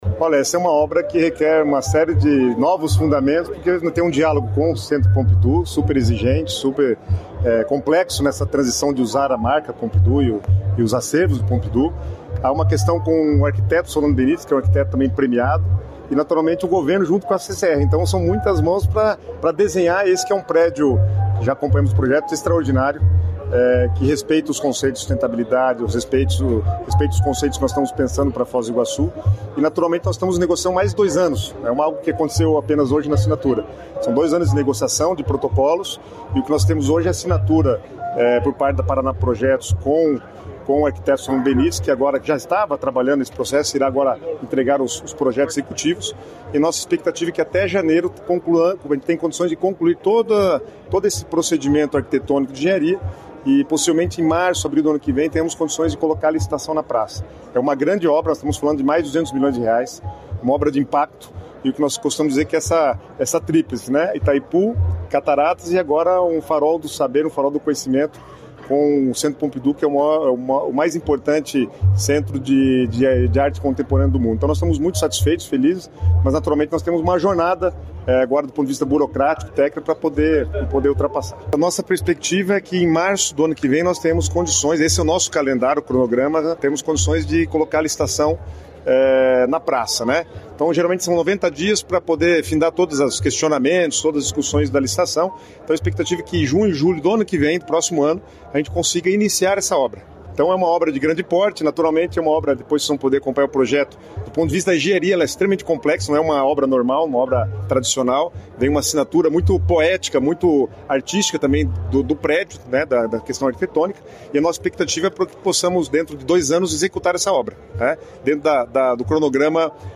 Sonora do secretário do Planejamento, Guto Silva, sobre o novo Pompidou no Paraná